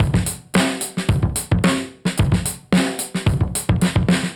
Index of /musicradar/dusty-funk-samples/Beats/110bpm/Alt Sound